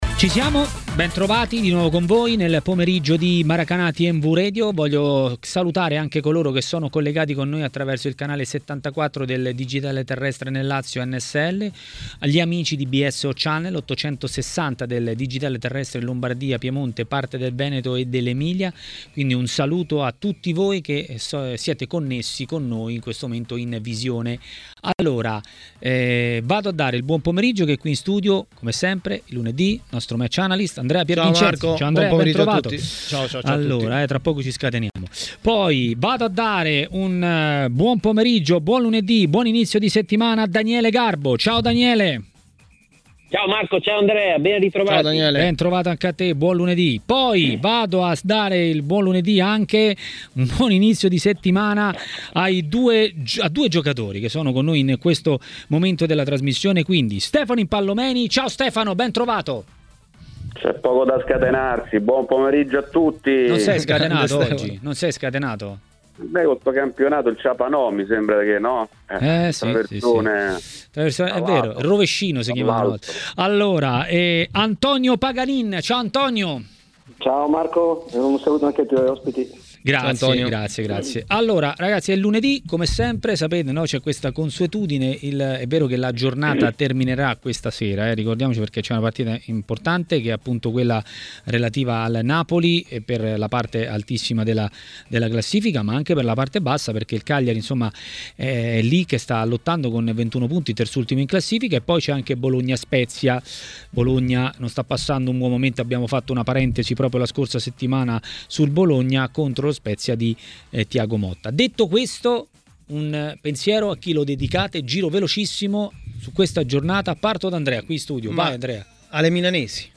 A Maracanà, nel pomeriggio di TMW Radio, l'ex calciatore Antonio Paganin ha parlato dell'ultimo turno di campionato.